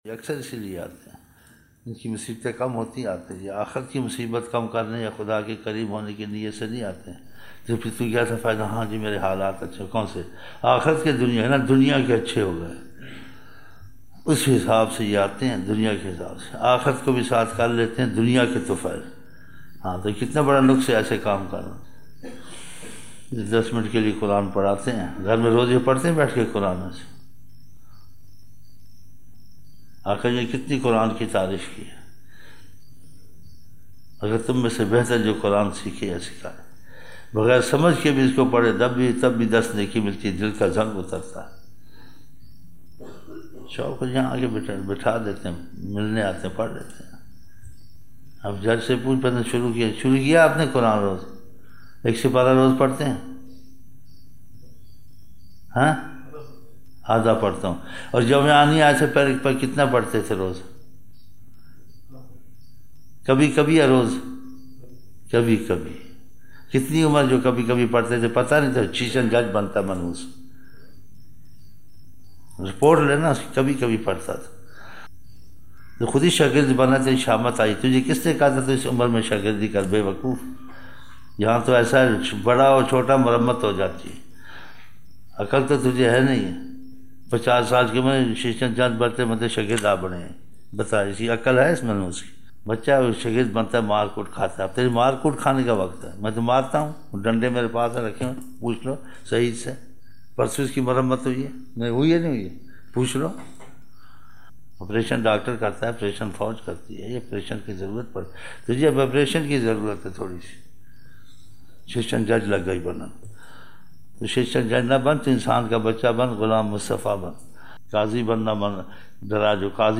21 November 1999 - Fajar mehfil (13 Shaban 1420)